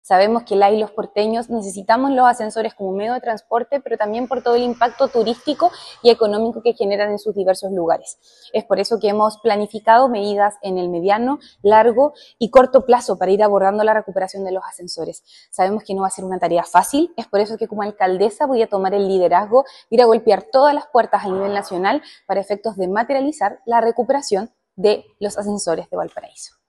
Ante ello, la alcaldesa Camila Nieto aseguró que se hará cargo de la tarea de recuperar los ascensores a través de medidas a corto, mediano y largo plazo.
cu-ascensores-alcaldesa-valparaiso.mp3